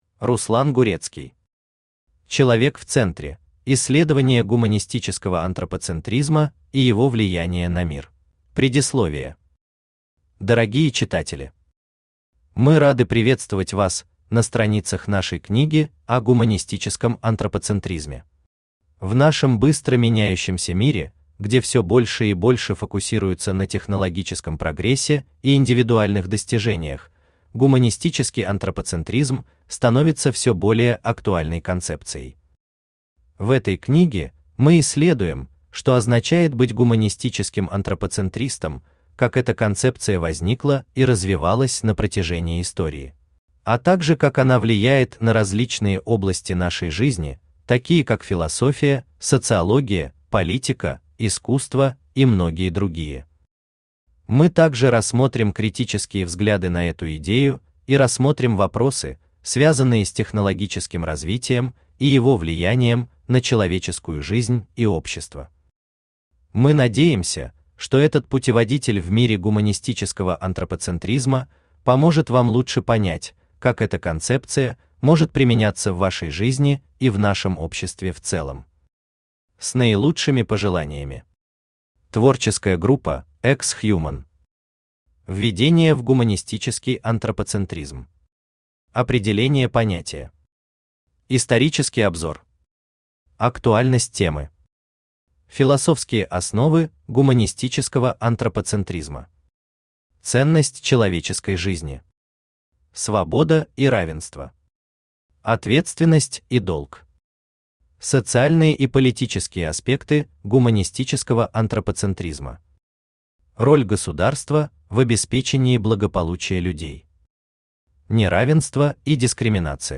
Аудиокнига Человек в центре: исследование гуманистического антропоцентризма и его влияния на мир | Библиотека аудиокниг
Читает аудиокнигу Авточтец ЛитРес.